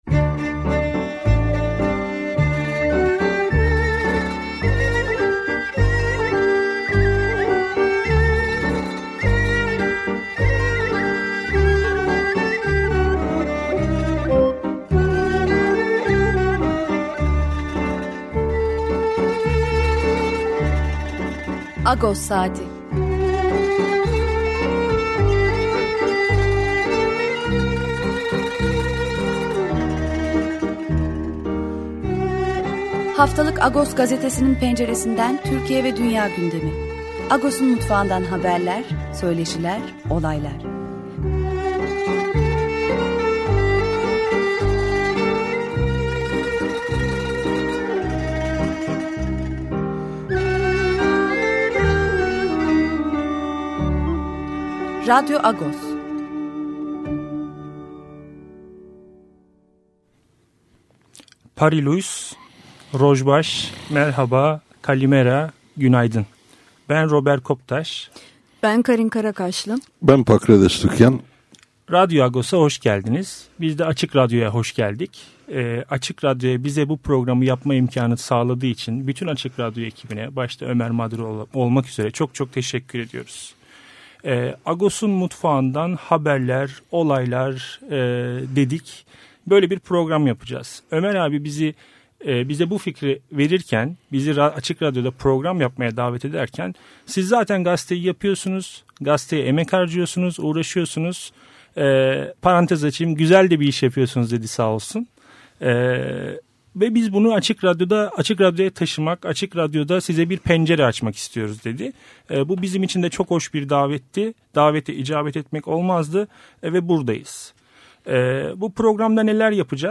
Açık Radyo’nun yeni yayın döneminde her Cumartesi sabahı saat 9:00 – 10:30 arasında, Türkiye ve dünya gündemini haftalık Agos gazetesinin penceresinden takip edecek ve Agos'un mutfağından haberlere, söyleşilere, olaylara ve üzerinde yaşadığımız coğrafyanın şarkılarına yer verecek olan Radyo Agos bu hafta başladı.